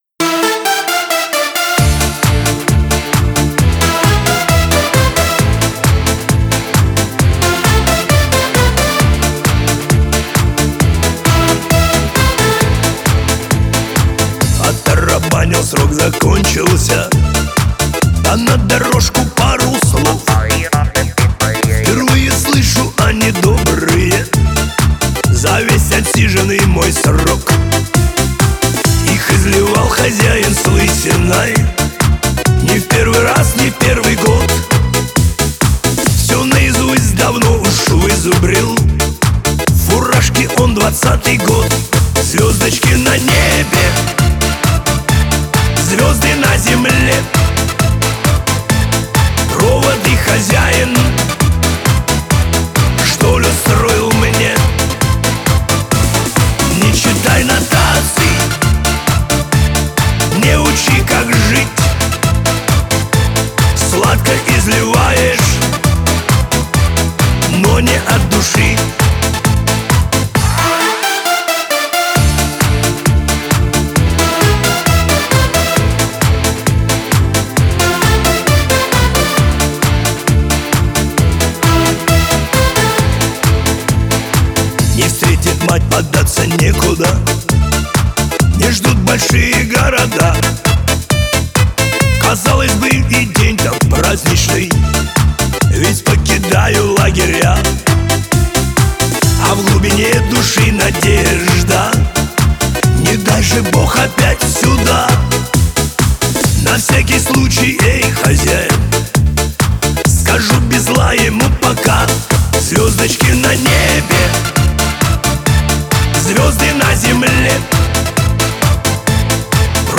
Главная » Музыка » Шансон